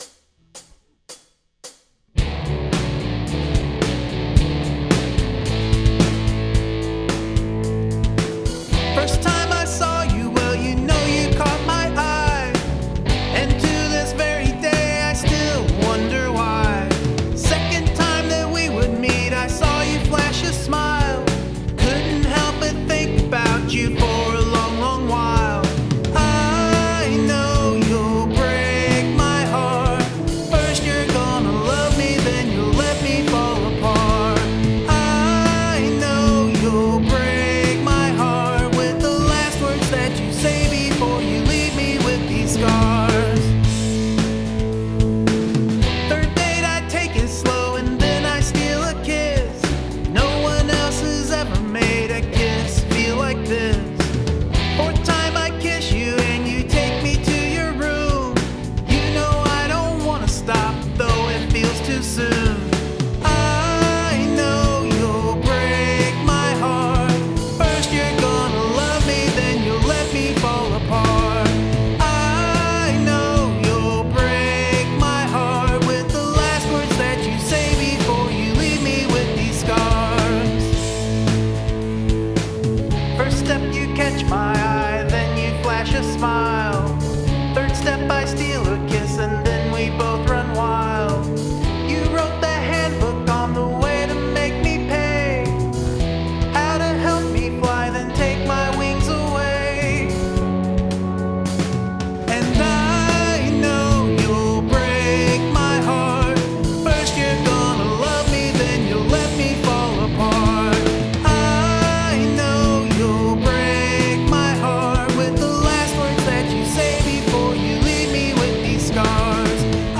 really like the guitar tone on this one - good and grungy